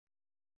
♪ saggadoṛe